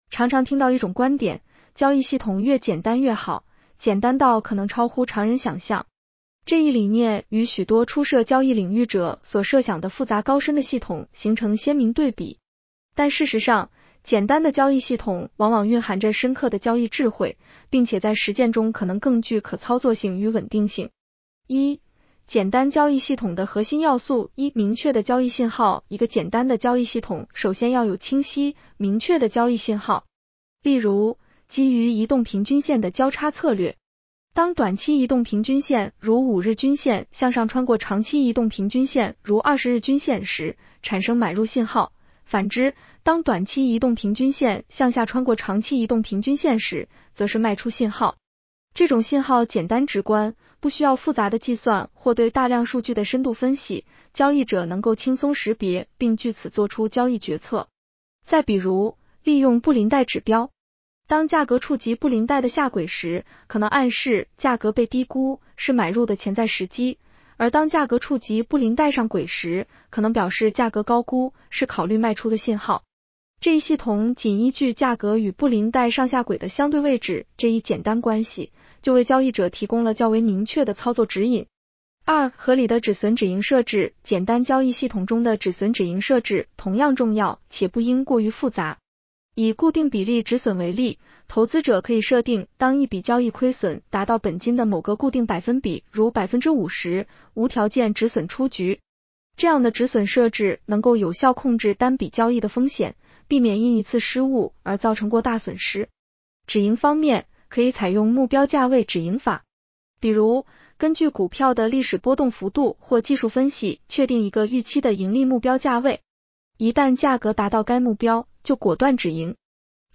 【期货交易夜读音频版】 女声普通话版 下载mp3 常常听到一种观点：交易系统越简单越好，简单到可能超乎常人想象。